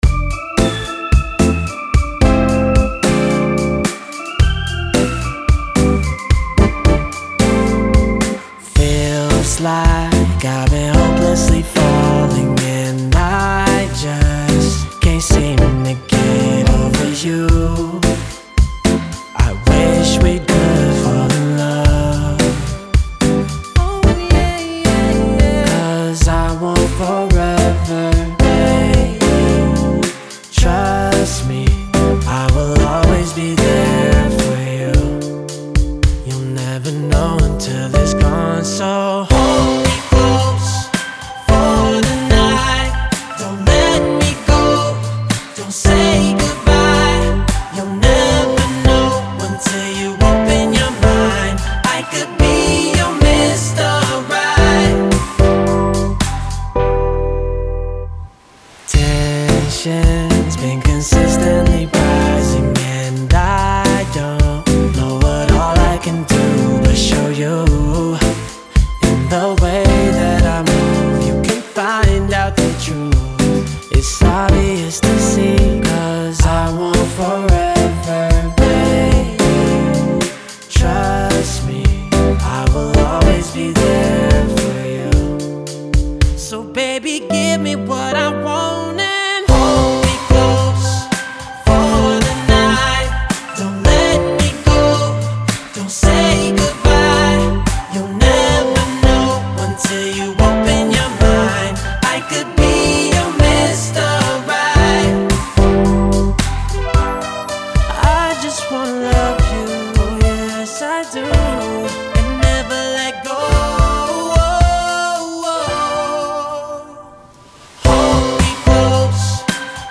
Genre: Pop
especially the electric piano sound
First, I would really like to hear more of the bass track.